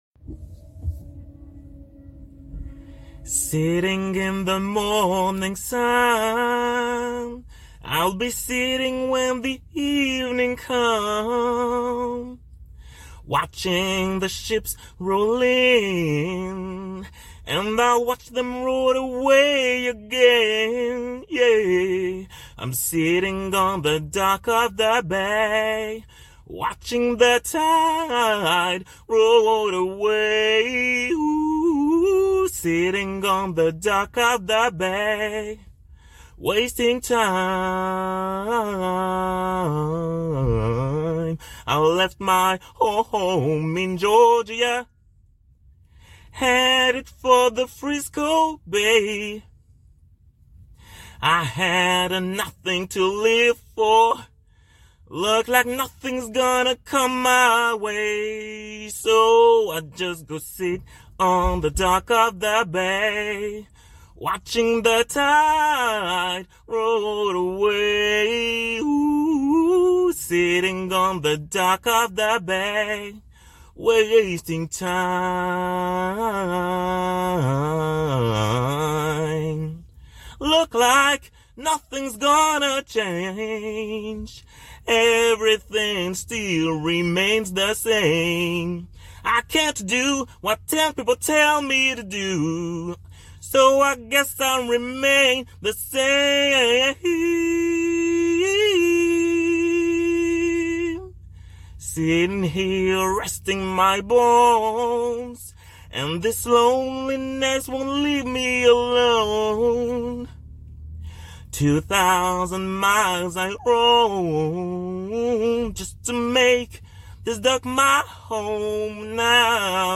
15 - 80 ans - Baryton